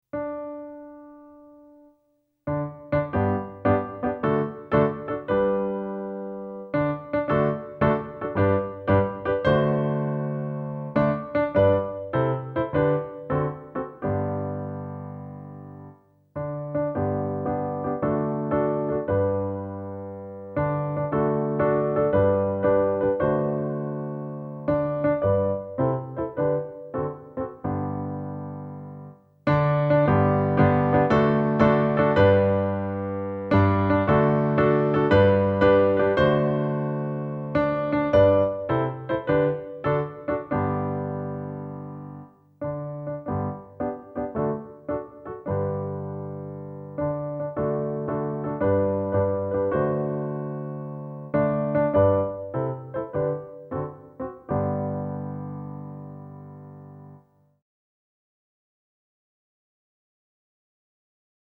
Zongora variáció